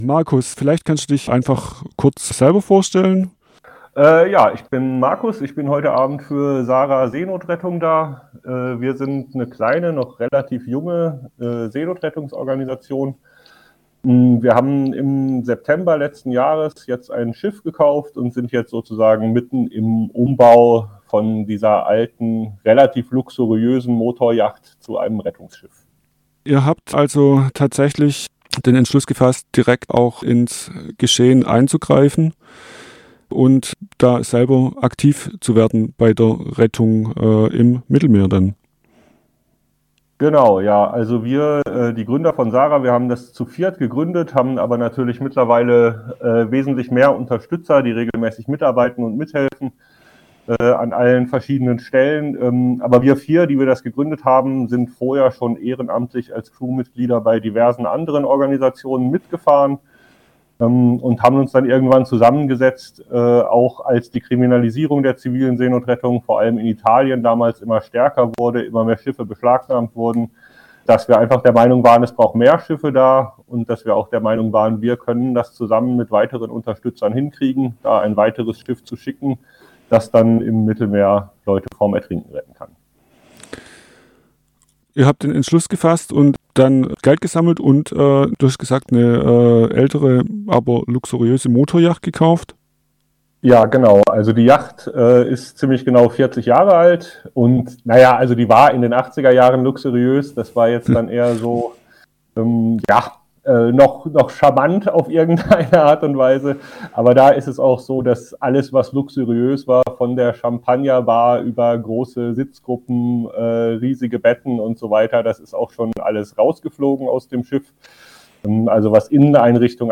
Interview Teil 1